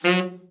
TENOR SN  11.wav